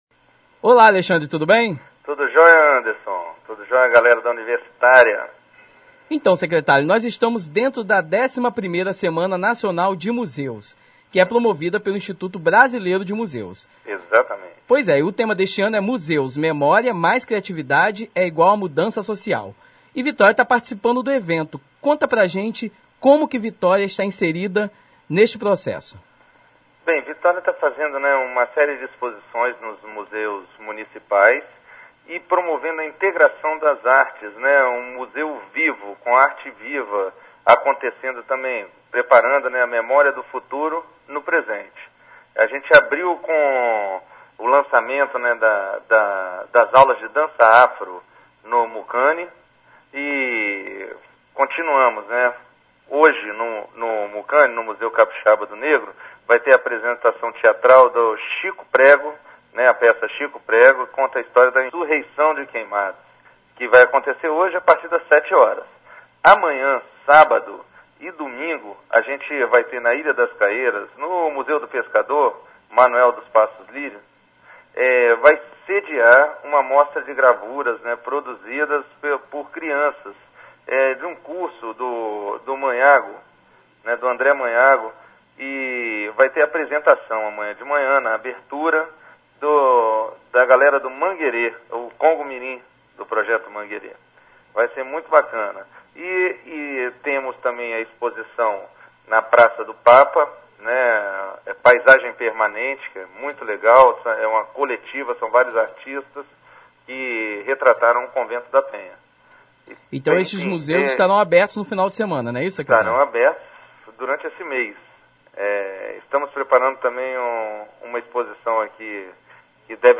Confira a entrevista com o secretário Alexandre Lima.
Entrevista Alexandre Lima